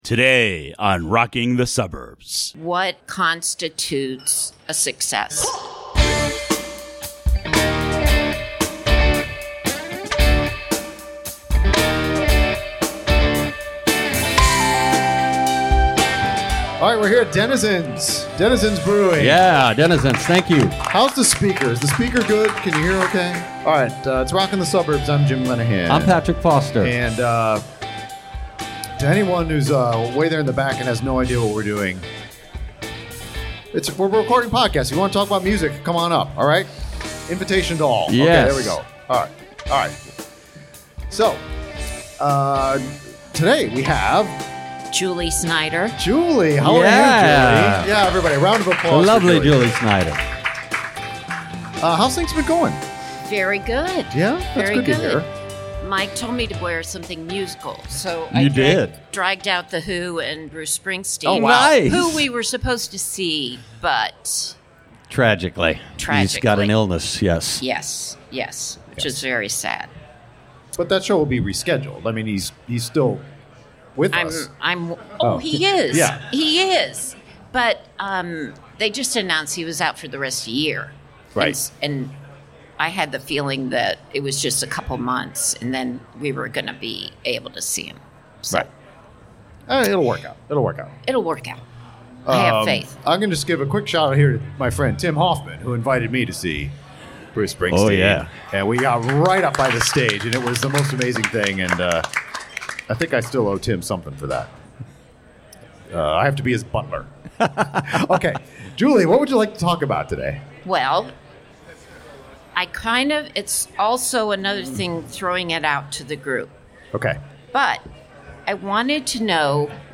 Live from Denizens Brewing Co. during Suburbs Fest DC, we present more podcasts!